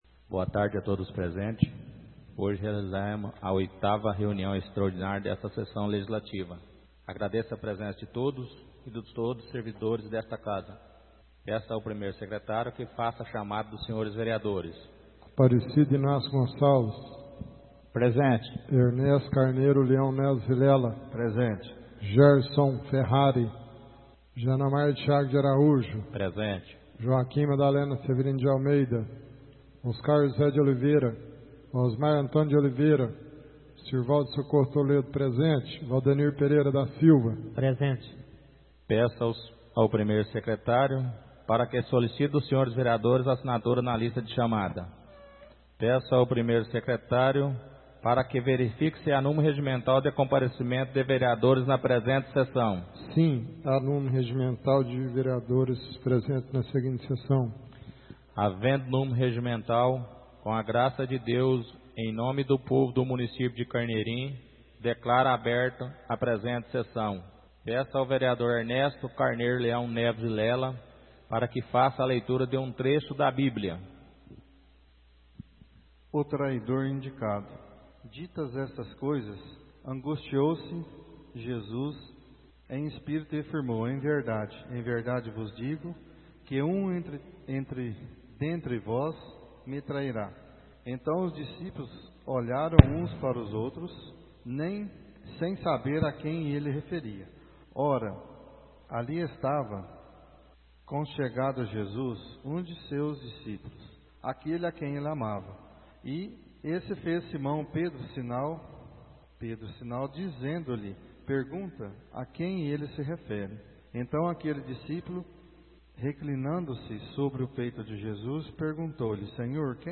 Áudio da 08ª reunião extraordinária de 2016, realizada no dia 14 de Dezembro de 2016, na sala de sessões da Câmara Municipal de Carneirinho, Estado de Minas Gerais.